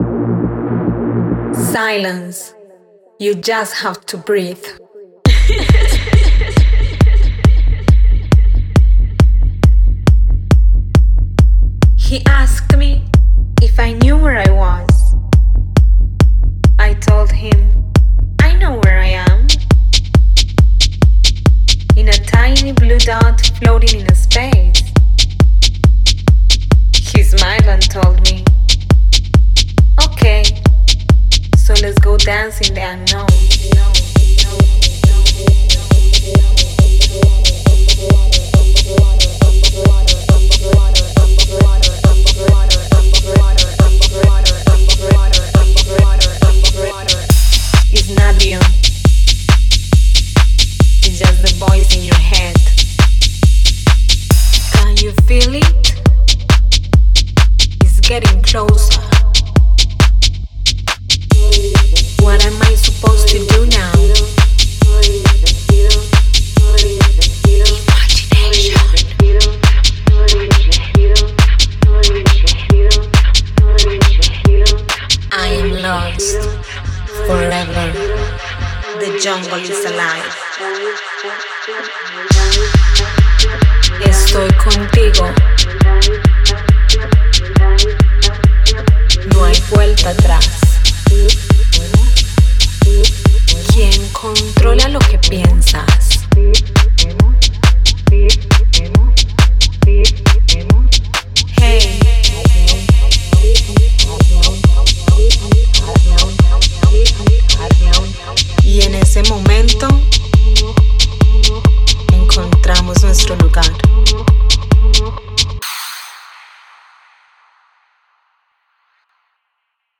Genre:Techno
デモサウンドはコチラ↓
26 Spoken Vocals (Phrases & Sentences) - Eng
10 Spoken Vocals (Phrases & Sentences) - Esp
15 Vocal Loops
10 Effects